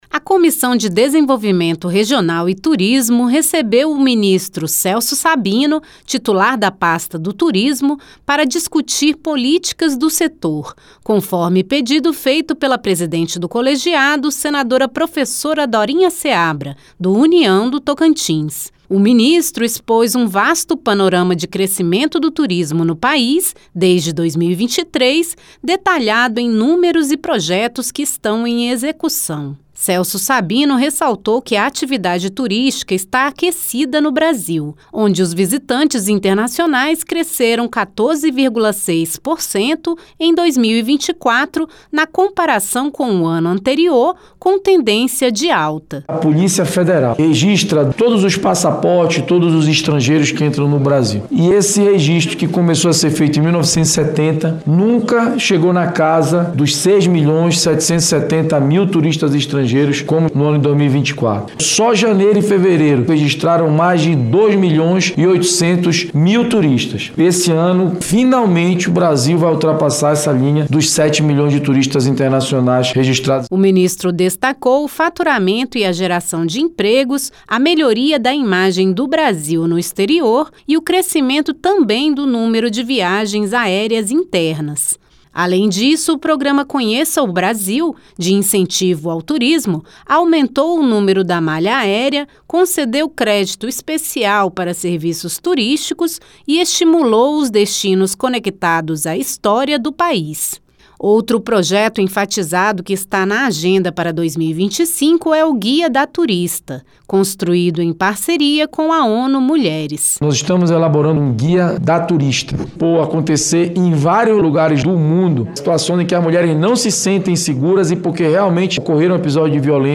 A pedido da presidente da Comissão de Desenvolvimento Regional e Turismo (CDR), senadora Professora Dorinha Seabra (União-TO), o ministro do Turismo, Celso Sabino, participou de audiência pública no colegiado nesta terça (18). Ele afirmou que cenário do turismo no Brasil apresenta altos índices positivos desde 2023 e explicou programas já em execução pelo ministério, além de projetos para este ano.